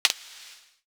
Sizzle Click 5.wav